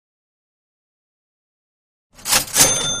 Cash Register
# money # retail # ding About this sound Cash Register is a free sfx sound effect available for download in MP3 format.
011_cash_register.mp3